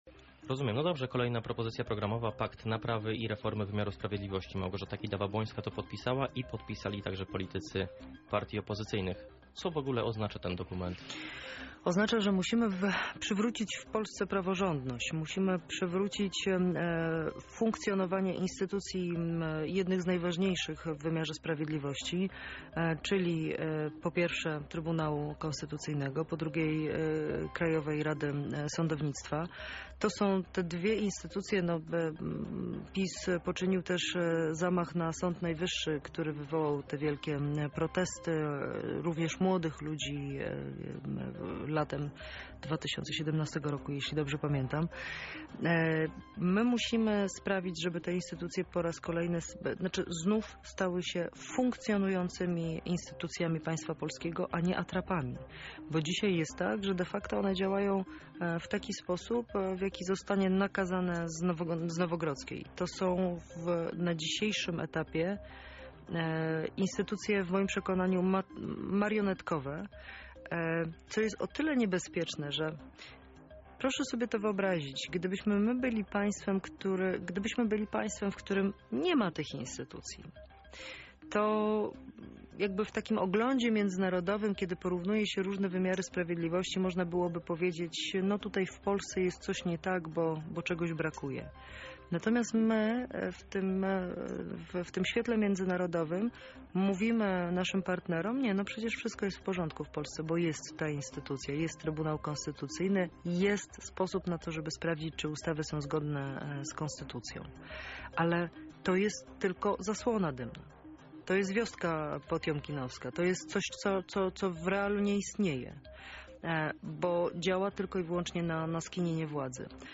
Posłanka Platformy Obywatelskiej, będąca jednocześnie „jedynką” listy Koalicji Obywatelskiej w naszym regionie była gościem Porannej Rozmowy Radia Centrum.
Poranna Rozmowa RC – Joanna Mucha cz. 2